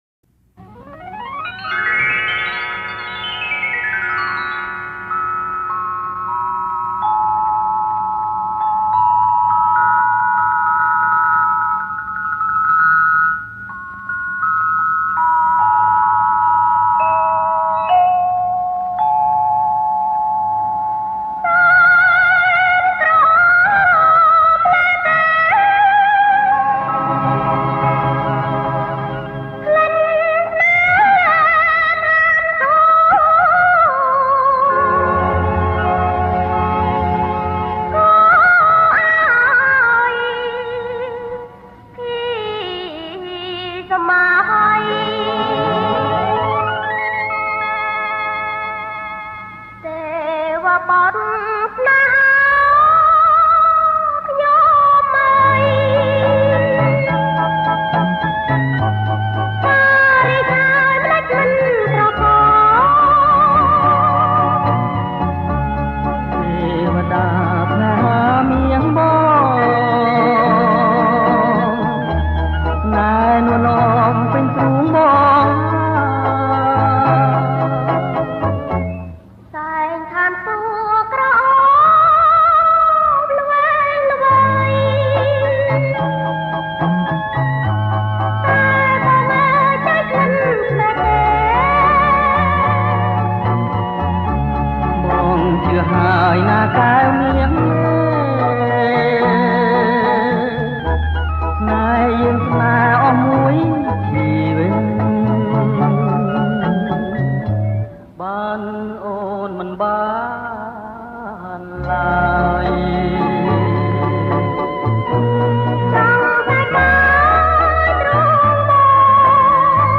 • ប្រគំជាចង្វាក់ Slow Rock
ប្រគំជាចង្វាក់ Slow Rock